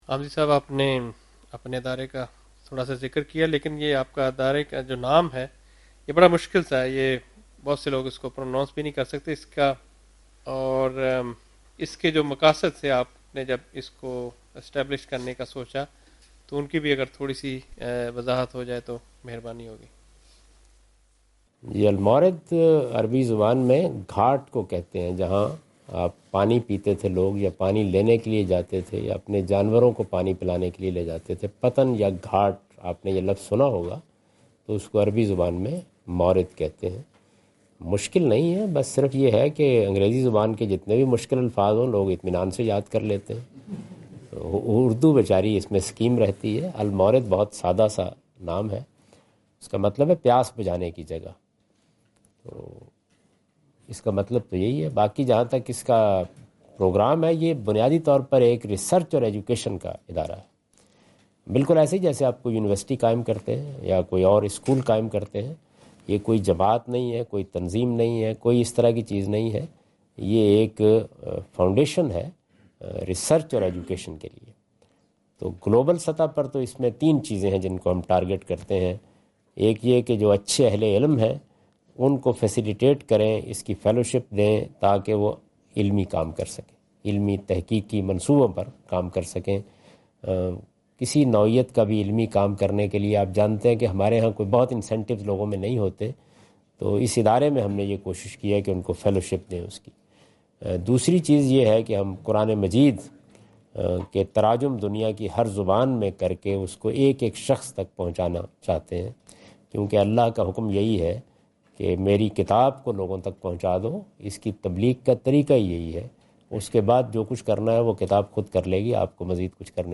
Javed Ahmad Ghamidi answer the question about "Missions of Al-Mawrid" during his Australia visit on 11th October 2015.
جاوید احمد غامدی اپنے دورہ آسٹریلیا کے دوران ایڈیلیڈ میں "المورد، ادارہ علم و تحقیق کے مقاصد" سے متعلق ایک سوال کا جواب دے رہے ہیں۔